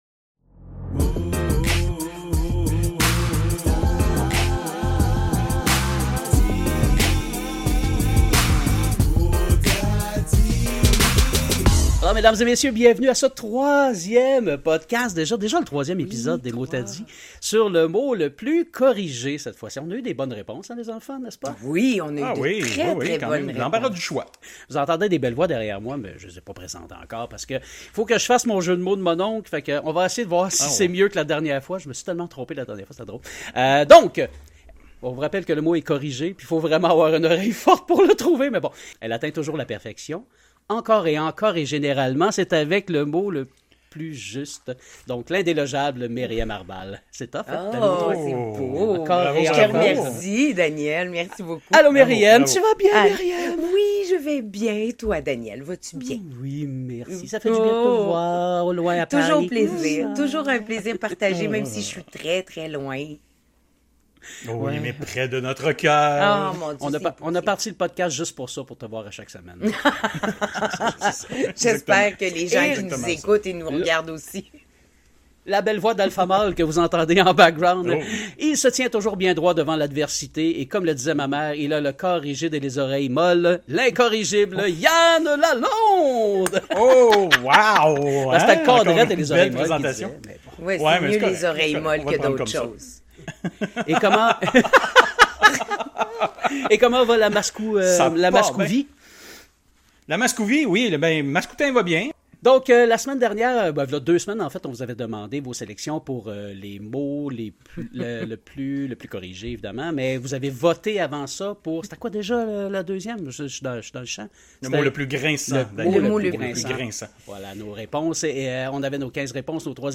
Entre arguments surprenants, rires et réflexions linguistiques, nos trois passionnés du langage s’affrontent et défendent leur choix inspiré par vos propositions.